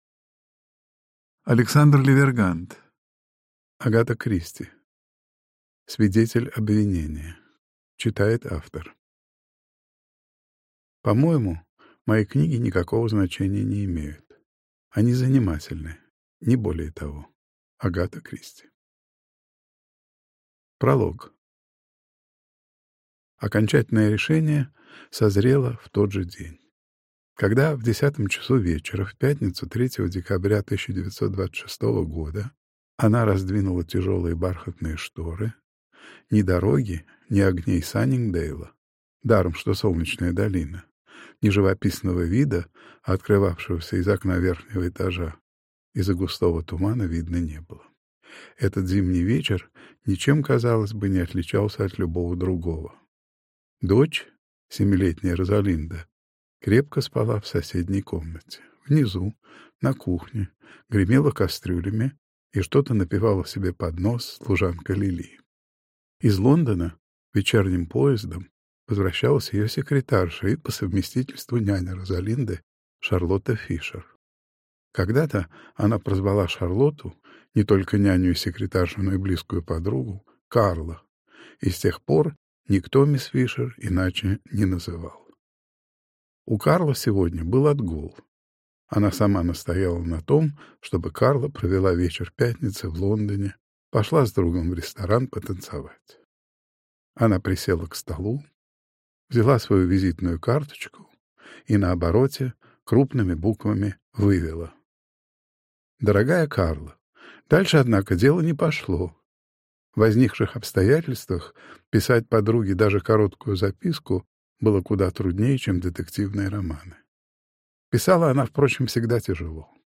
Аудиокнига Агата Кристи. Свидетель обвинения | Библиотека аудиокниг